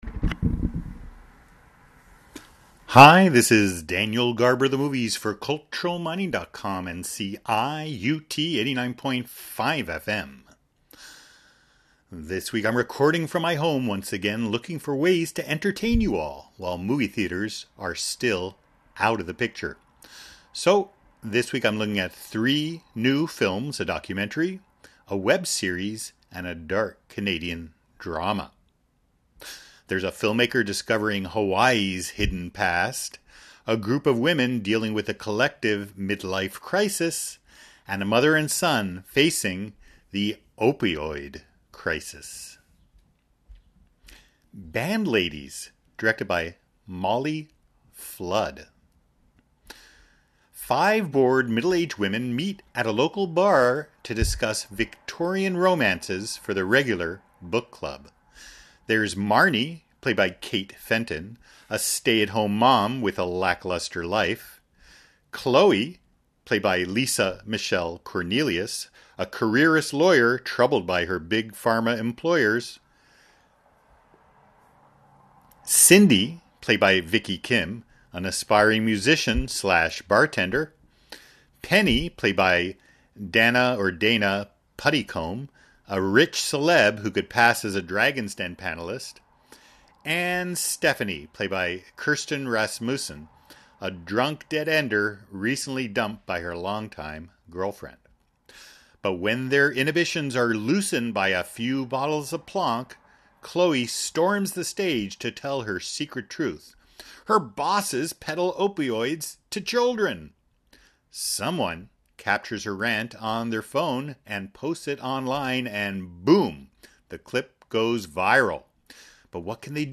(Audio: no music)